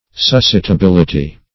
Search Result for " suscitability" : The Collaborative International Dictionary of English v.0.48: Suscitability \Sus`ci*ta*bil"i*ty\, n. Capability of being suscitated; excitability.
suscitability.mp3